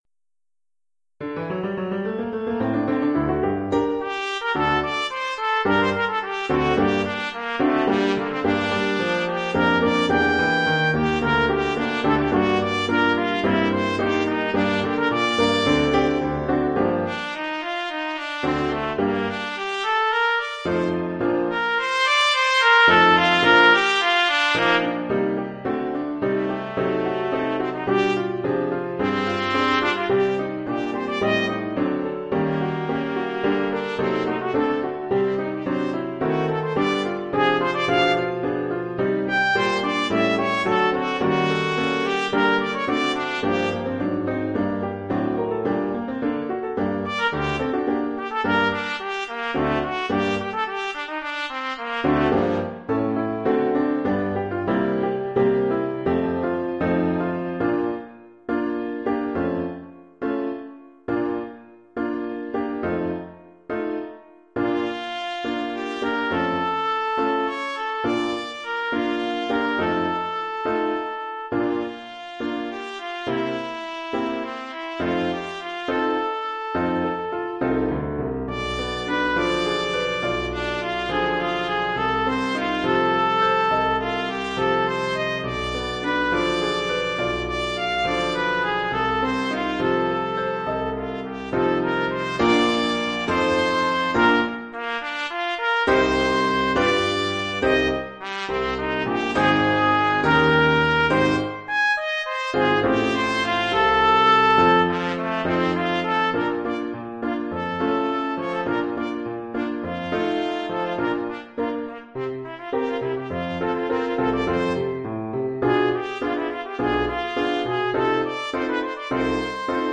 Formule instrumentale : Trompette et piano
Oeuvre pour trompette ou
cornet ou bugle et piano.